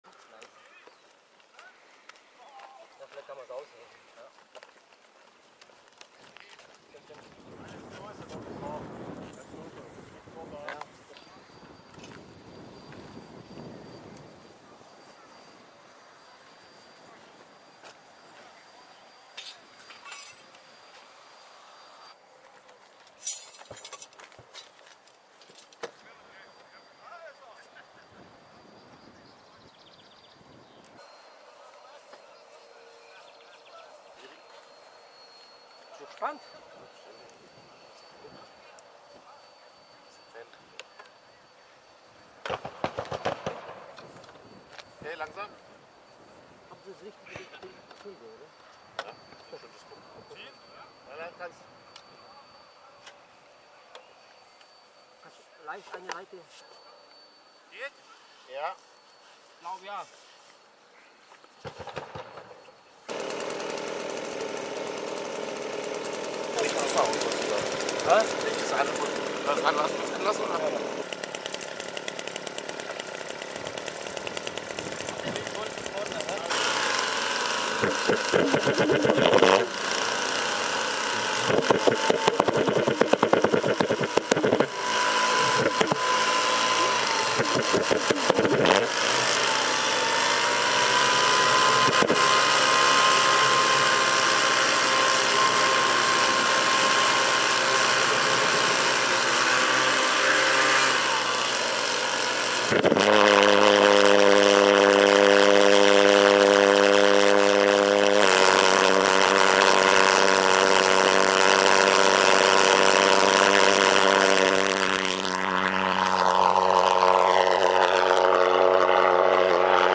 VERY HUGE AND LOUD PULSE JET HEINKEL HE-162 PULSO SCALE 1:3.5 MODEL AIRCRAFT / FLIGHT DEMONSTRATION